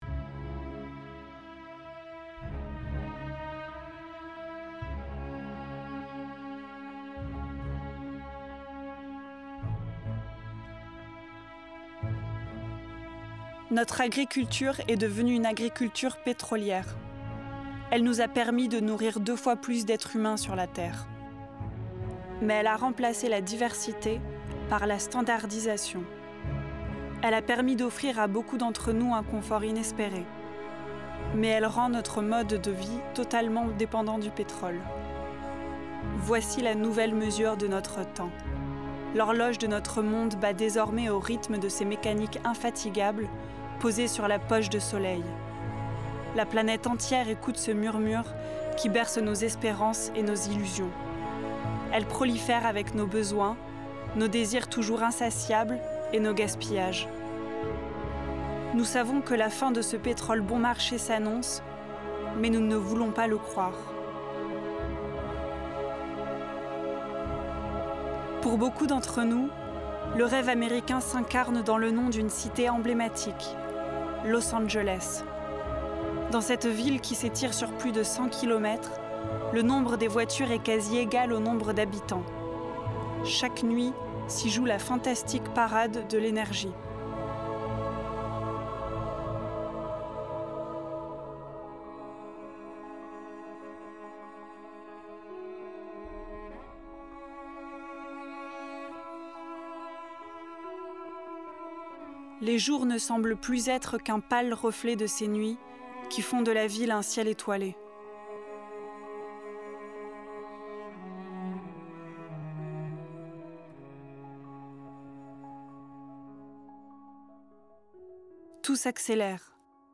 Pub
Narratrice
- Soprano